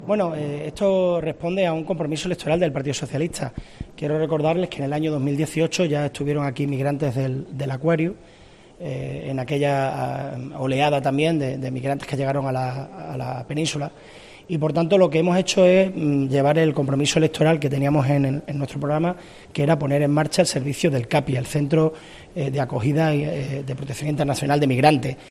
El alcalde también ha recordado, en declaraciones a los medios de comunicación antes de la presentación de las I Jornadas de Humanización en Cuidados Críticos, que en 2018 también acogieron a migrantes que llegaron a la Península Ibérica procedentes del buque Aquarius.